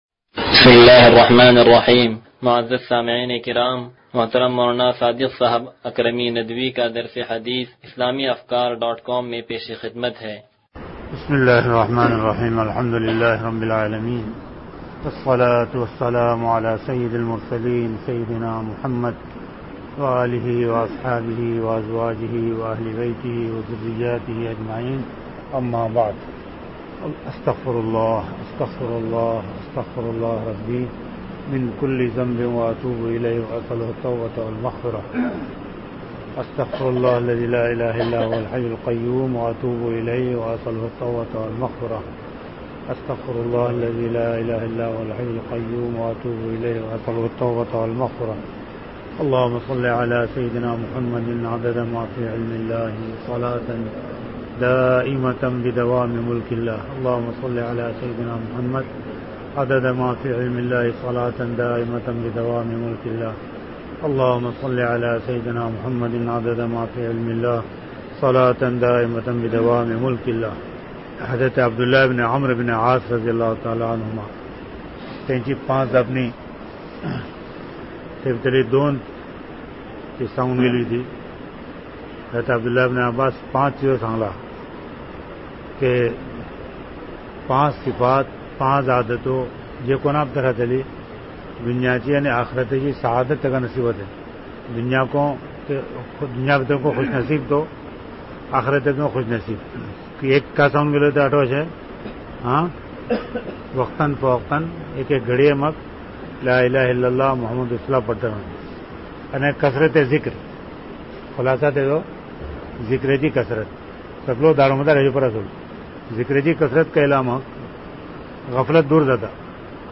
درس حدیث نمبر 0113
(تنظیم مسجد)